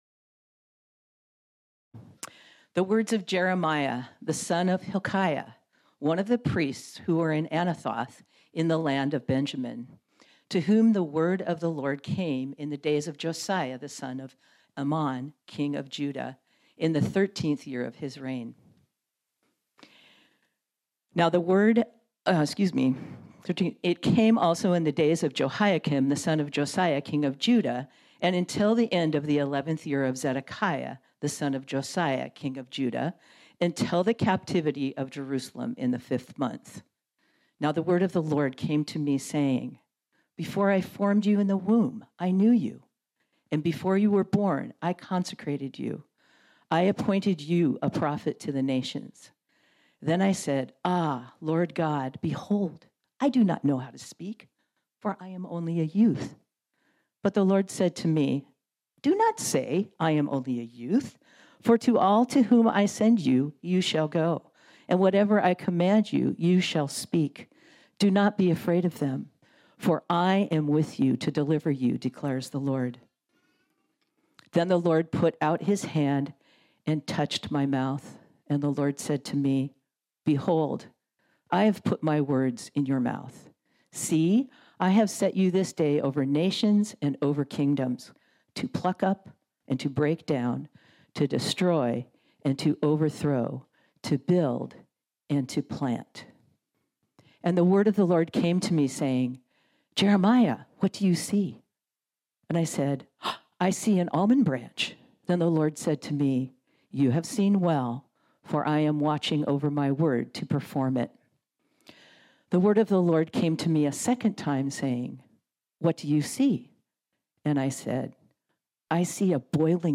This sermon was originally preached on Sunday, April 24, 2022.